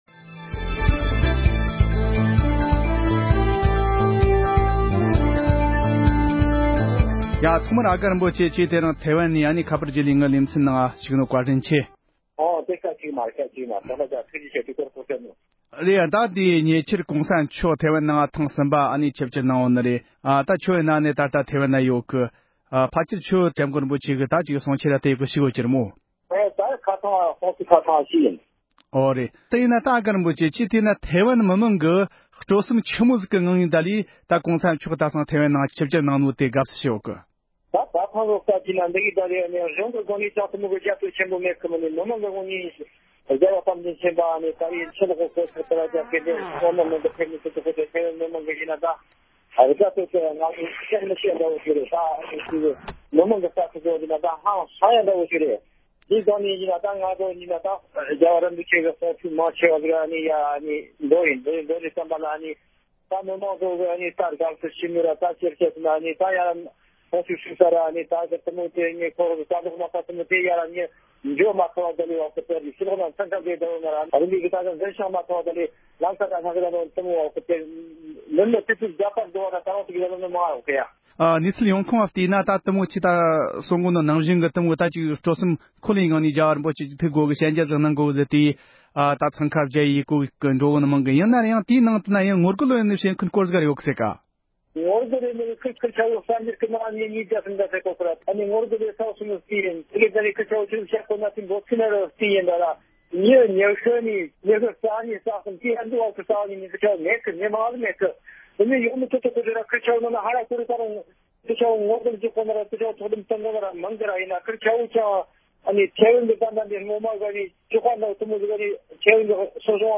༸གོང་ས་མཆོག་གི་ཐེ་དབན་ཆིབས་བསྒྱུར་དང་འབྲེལ་བའི་བགྲོ་གླེང༌།